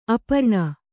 ♪ aparṇa